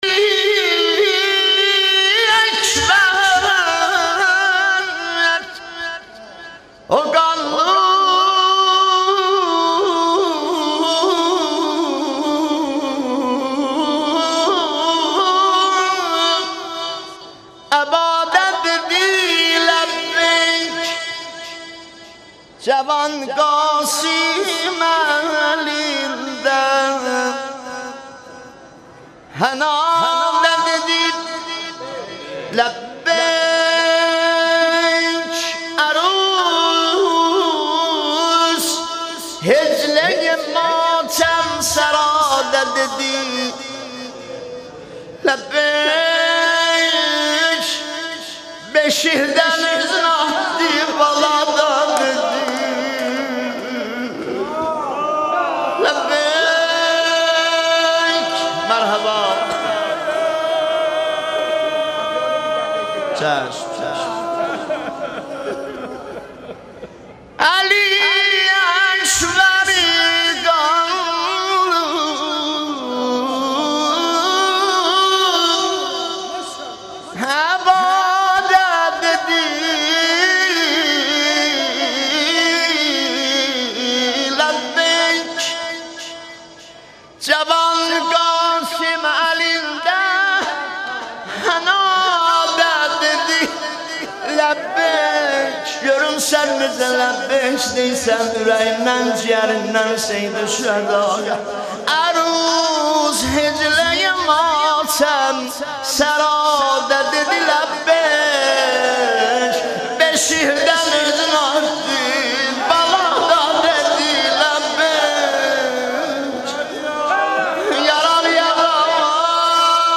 شب چهارم محرم مداحی آذری نوحه ترکی